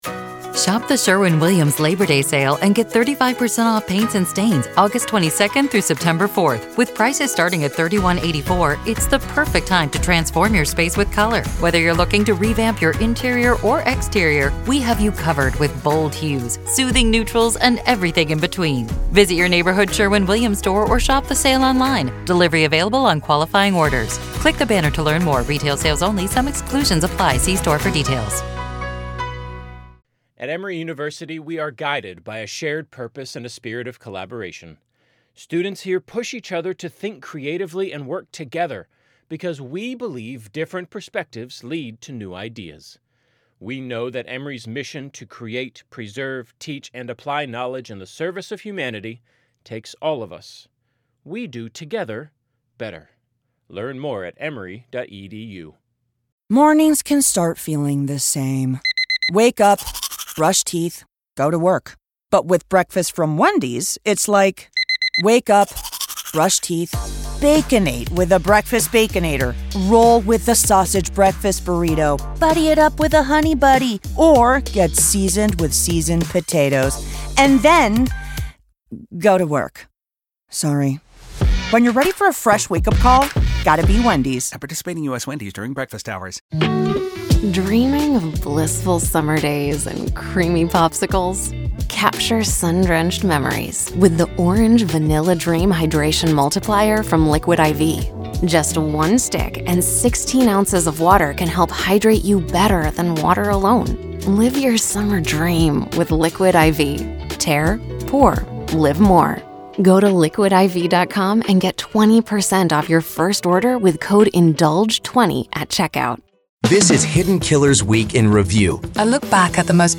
Welcome to the "Week in Review," where we delve into the true stories behind this week's headlines.